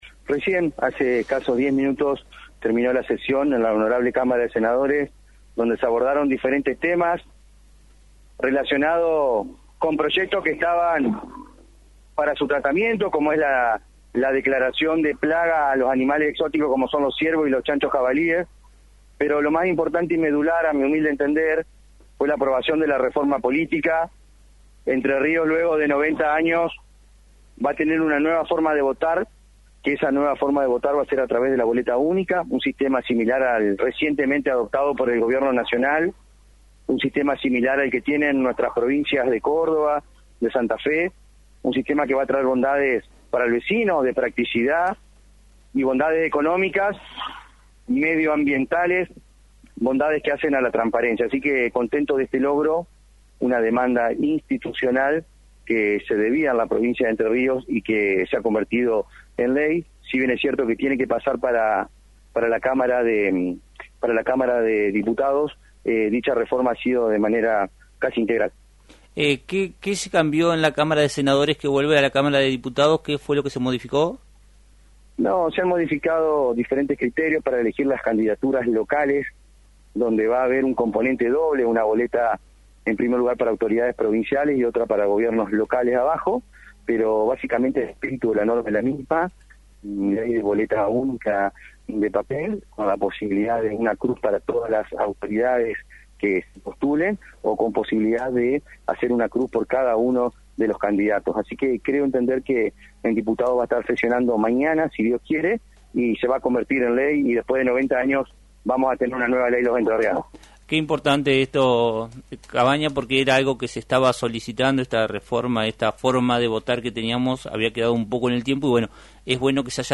“Después de 90 años, Entre Ríos contará con un sistema de votación moderno, que favorece la transparencia, la simplicidad y el cuidado del medio ambiente. Dejamos atrás las boletas largas, que no solo eran complicadas, sino que también generaban gastos innecesarios y molestias.”, manifestó esta tarde el senador Rafael Cabagna en el programa “Puntos Comunes”, de Radio Victoria.
Rafael Cavagna – Senador Provincial